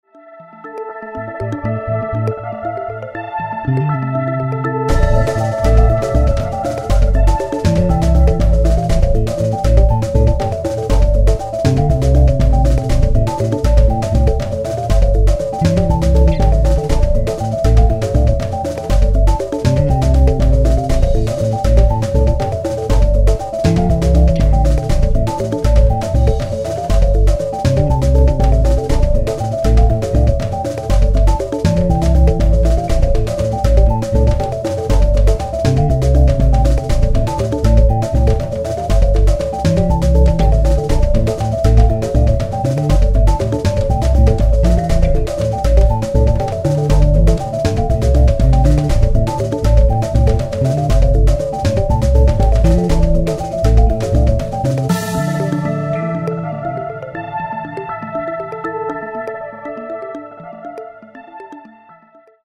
moody atmospheric grooves